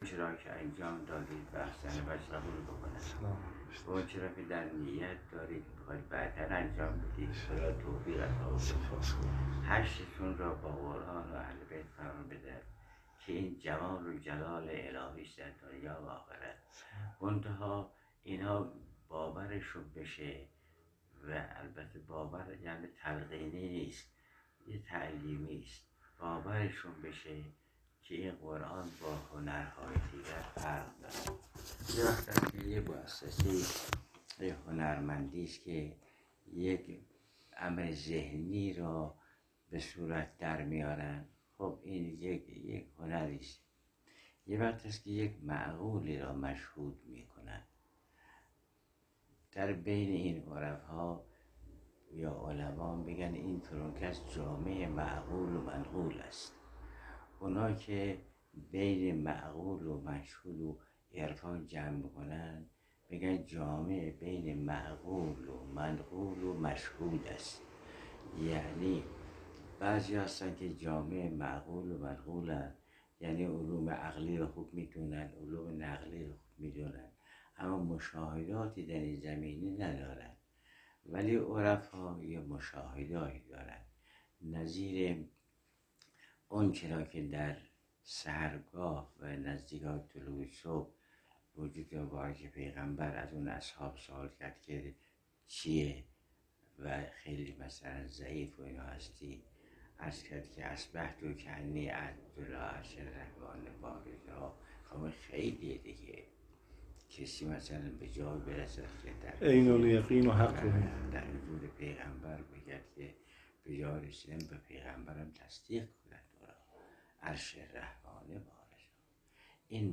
دقایقی از سخنان آیت‌الله العظمی جوادی‌آملی در این دیدار را می‌شنویم؛